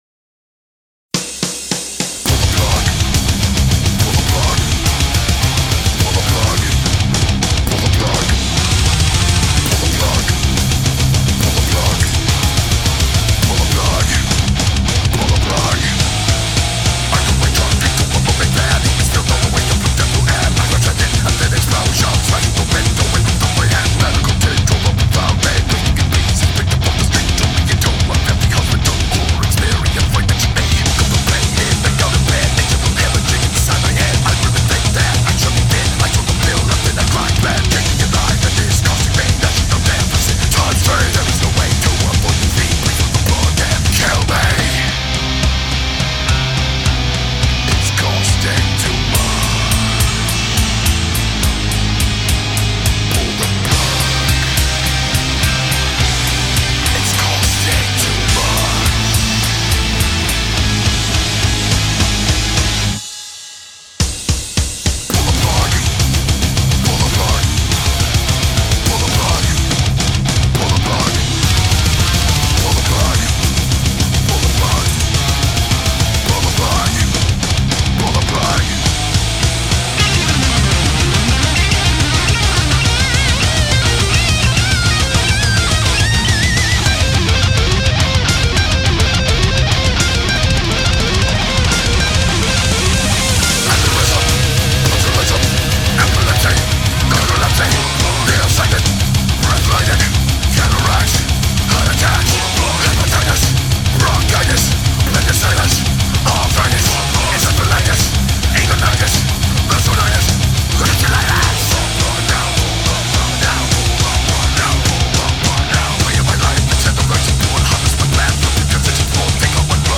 BPM52-210
Audio QualityCut From Video